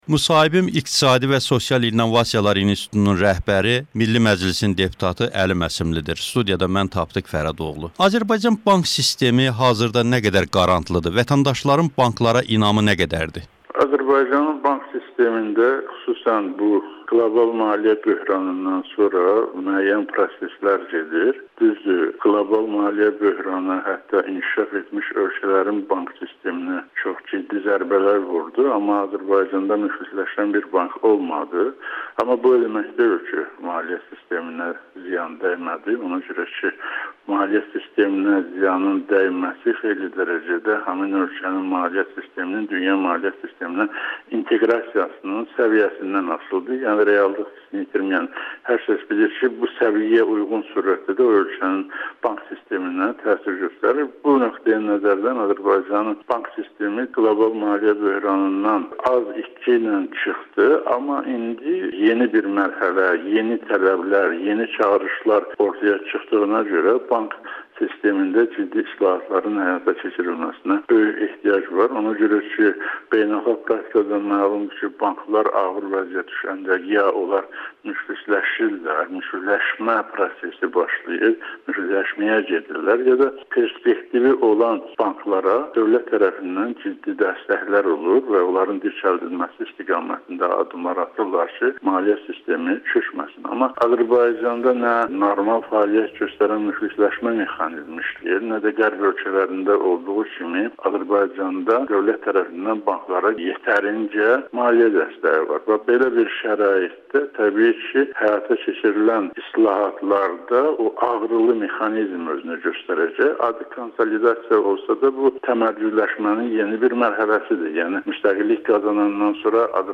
Milli Məclisin deputatı Əli Məsimli ilə müsahibə